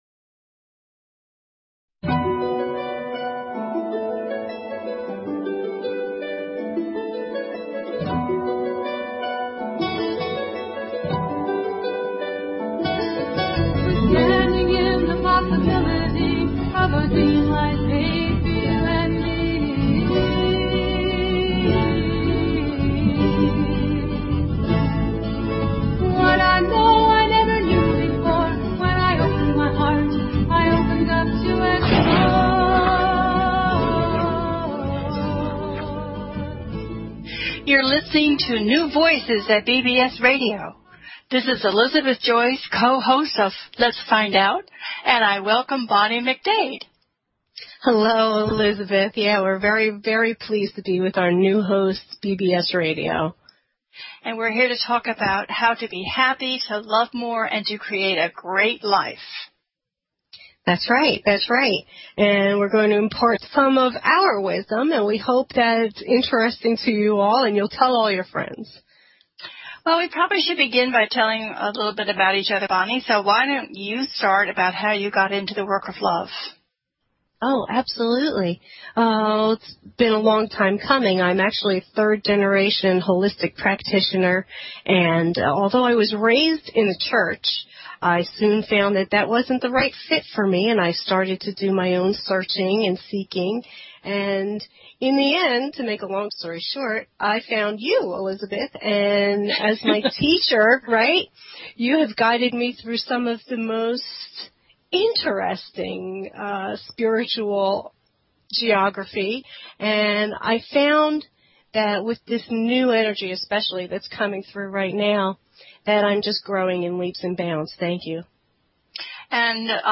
Talk Show Episode
Let’s Find Out brings to its listeners illuminating and enthralling exploration of the connection between our minds and our bodies. This show brings a series of fascinating interviews with experts in the field of metaphysics.
The listener can call in to ask a question on the air.